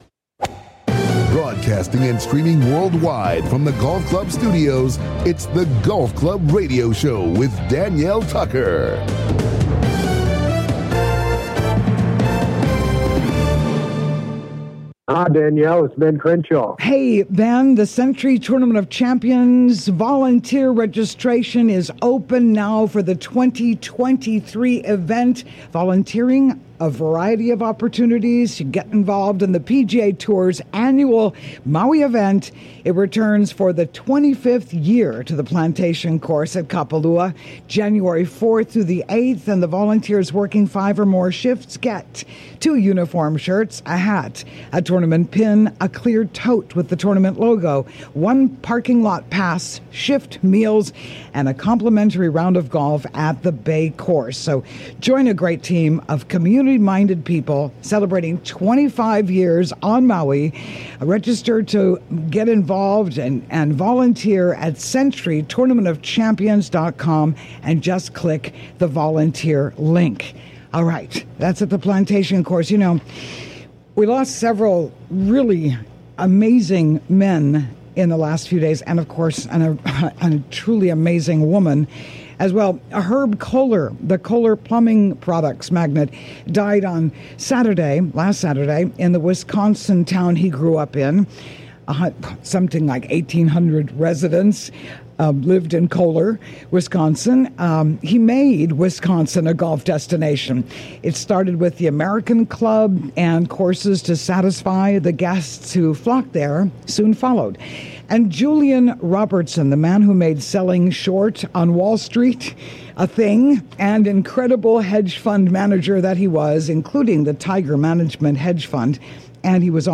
COMING TO YOU LIVE FROM THE GOLF CLUB STUDIOS ON LOVELY OAHU � WELCOME INTO THE CLUBHOUSE!